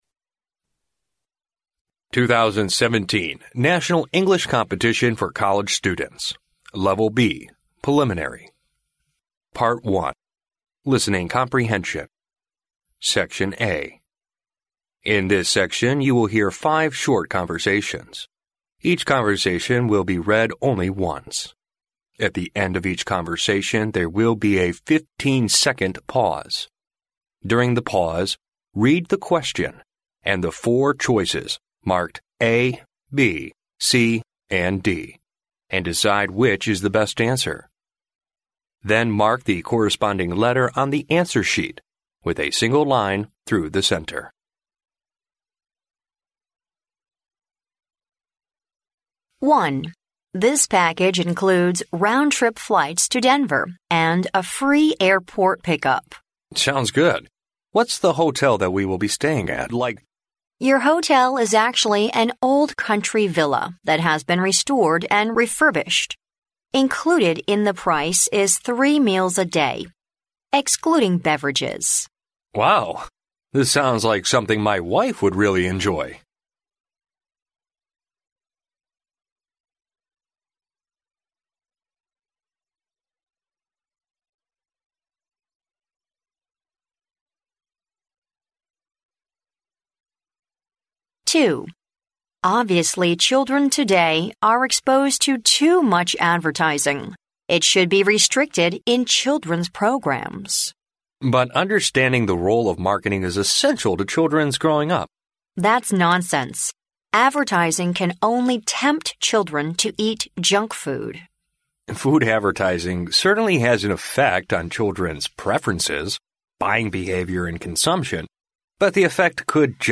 In this section, you will hear five short conversations. Each conversation will be read only once. At the end of each conversation, there will be a fifteen-second pause.